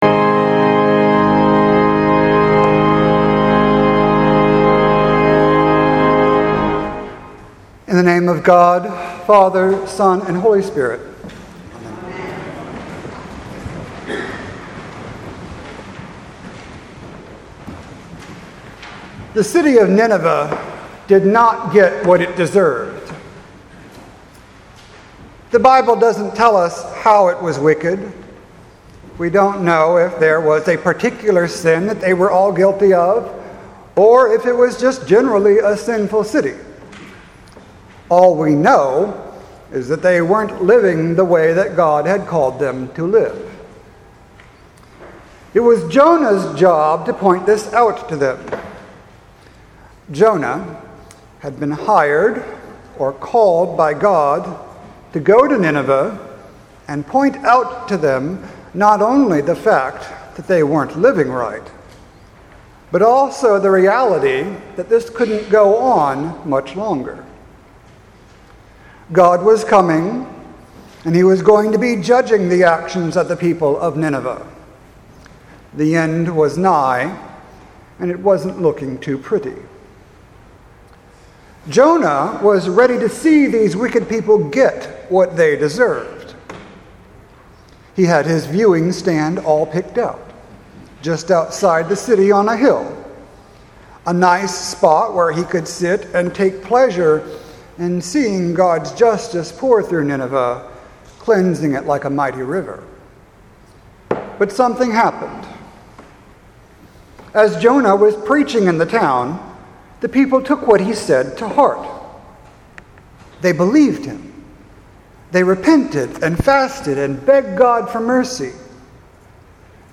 Sermon for September 24th, 2017
sermon-9-24-17.mp3